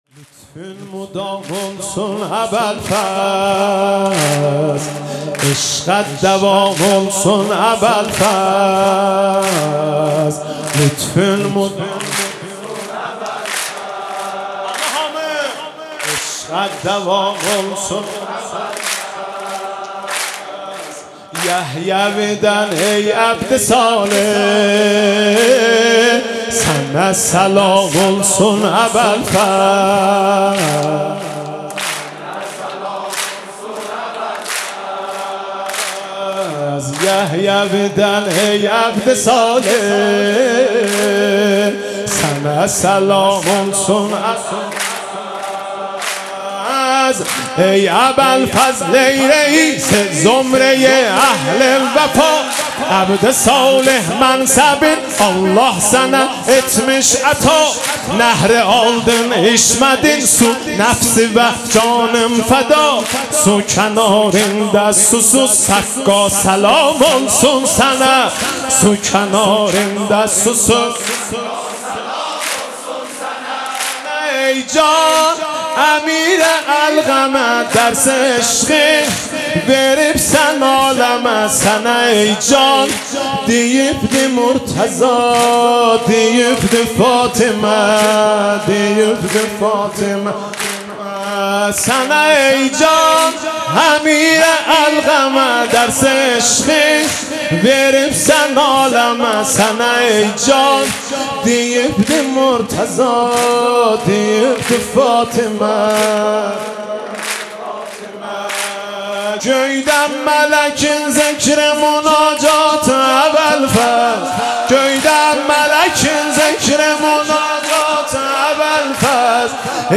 مولودی آذری